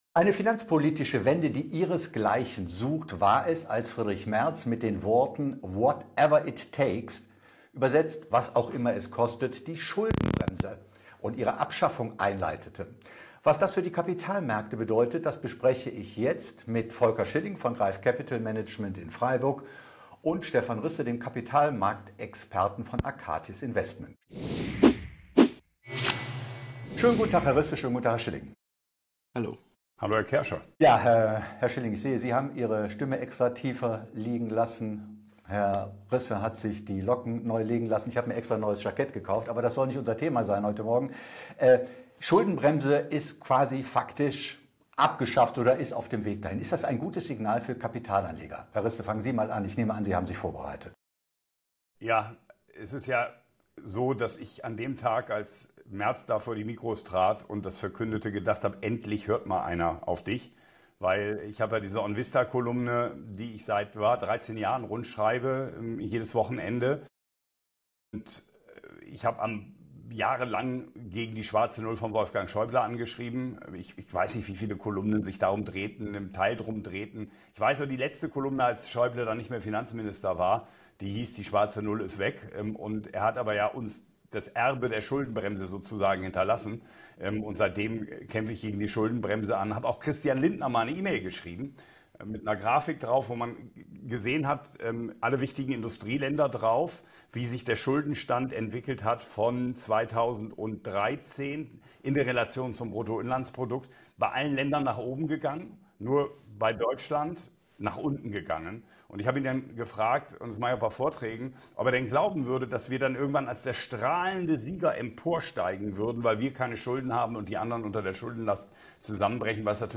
Ein unterhaltsames, meinungsstarkes und faktenreiches Gespräch, das nicht nur informiert, sondern zum Mitdenken (und Mitdiskutieren) anregt.